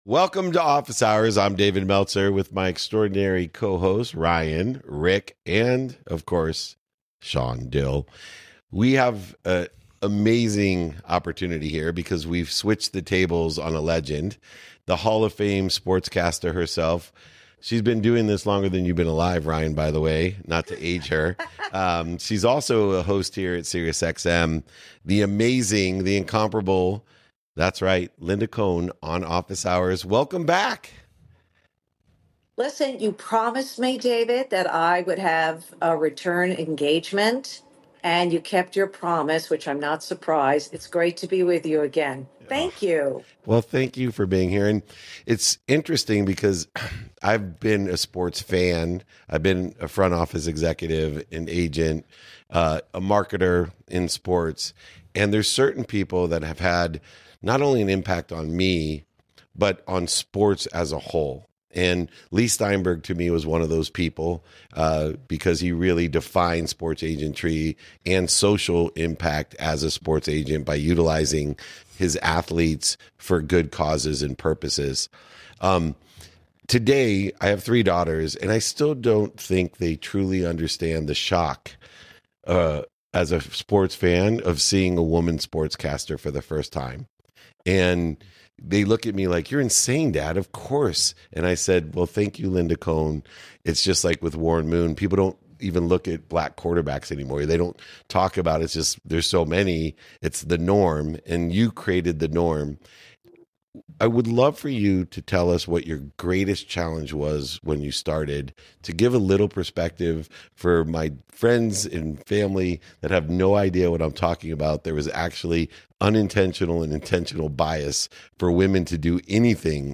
In today’s episode, I sit down with the legendary Linda Cohn, the longest-tenured anchor in ESPN history and a true trailblazer in sports media. Linda shares what it was like breaking into the industry as one of the only women in the room, the personal toll it took, and how she powered through early rejection with a mix of grit, humor, and love for the game. We talk about the pressure of live TV, her adrenaline-fueled nights on SportsCenter, and what keeps her coming back after more than 30 years.